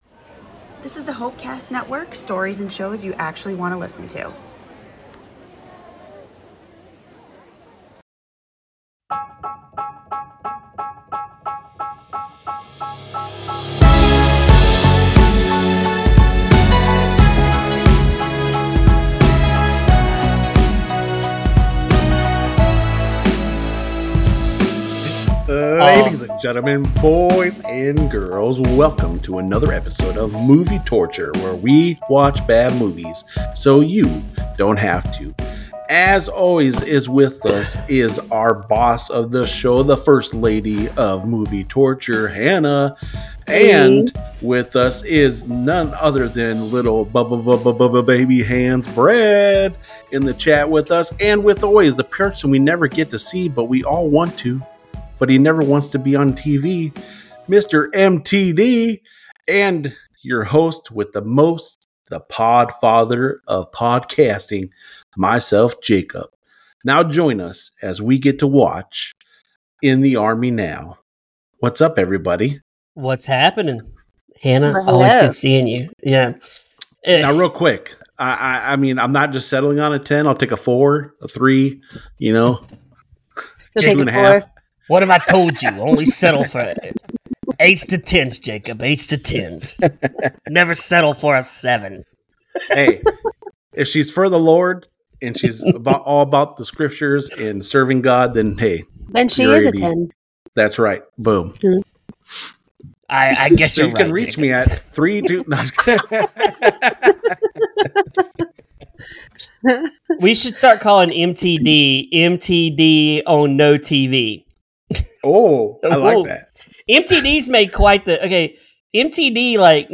Join us for a laugh-filled discussion as we navigate the absurdity of this 90s classic and ponder the real lessons learned from shoveling snow in the military.